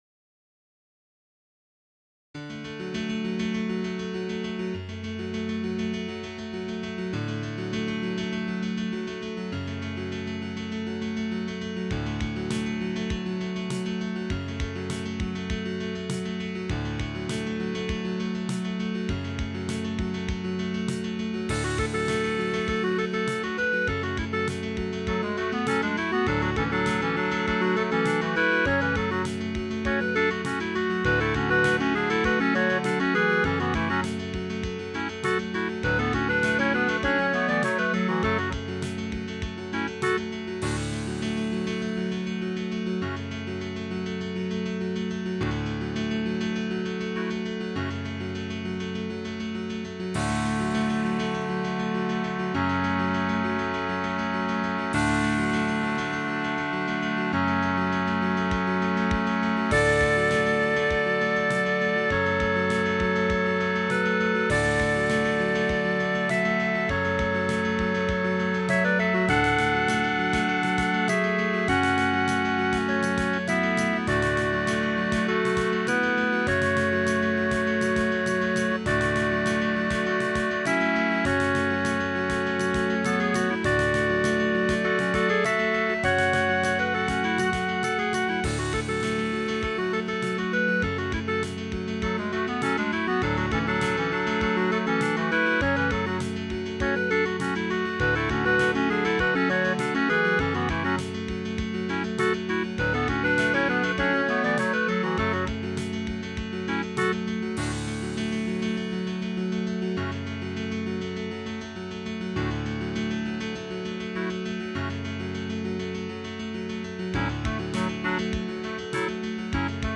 Tags: Trio, Piano, Clarinet, Percussion
Title Clarinet Trio Opus # 60 Year 2002 Duration 00:04:18 Self-Rating 3 Description This is has become one of my favorites among my music. It's … breeezy. mp3 download wav download Files: mp3 wav Tags: Trio, Piano, Clarinet, Percussion Plays: 2202 Likes: 0
060 Clarinet Trio.wav